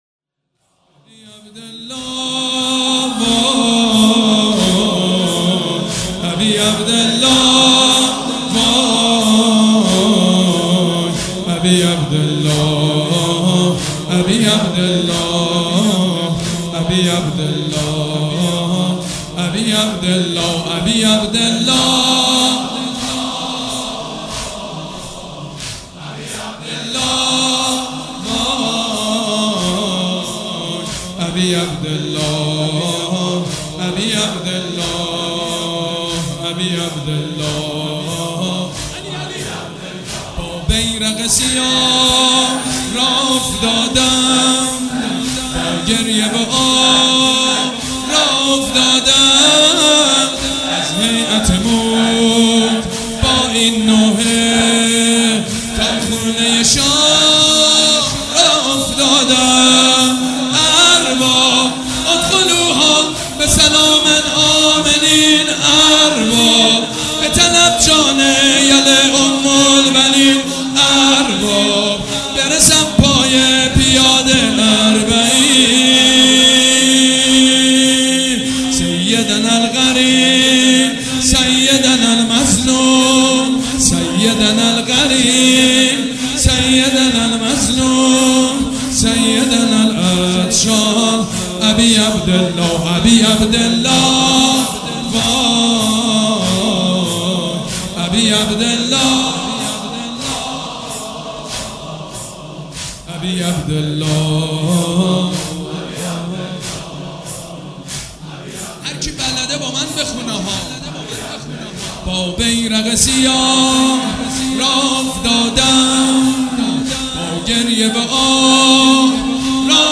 شب چهارم محرم الحرام‌
مداح
حاج سید مجید بنی فاطمه
مراسم عزاداری شب چهارم